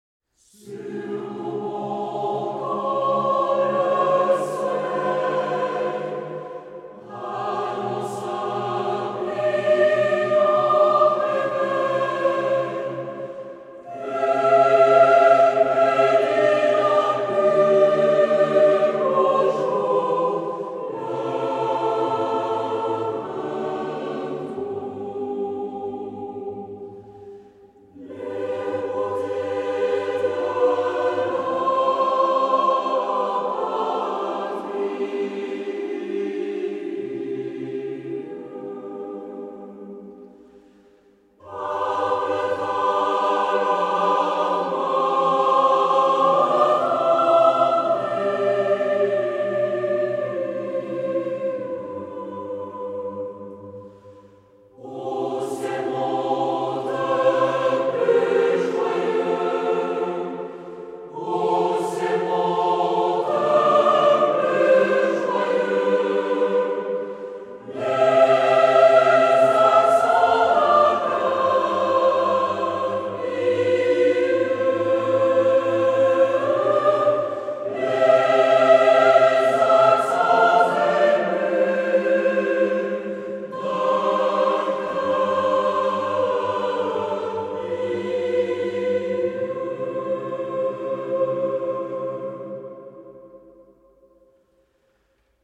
Ensemble Vocal de Villars-sur-Glâne.